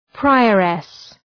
Προφορά
{‘praıərıs}